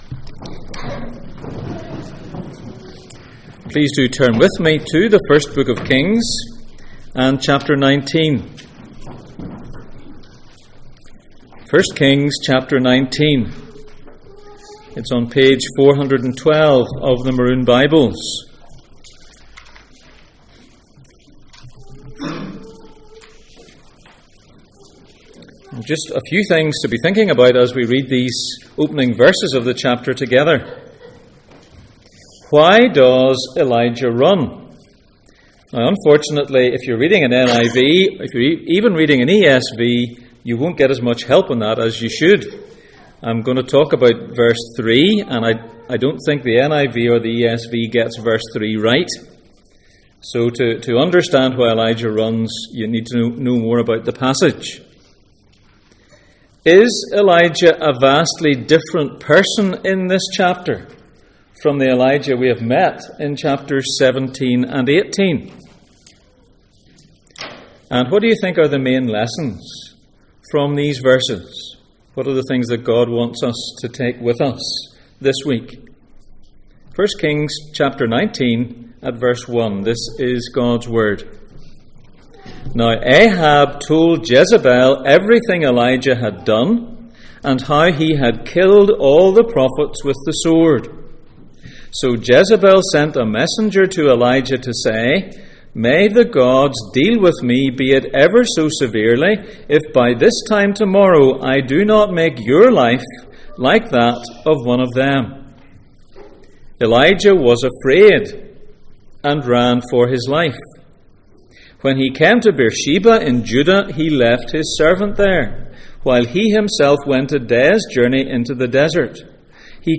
Passage: 1 Kings 19:1-18 Service Type: Sunday Morning